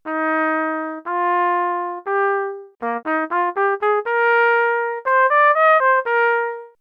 2. Performed by the CSIS model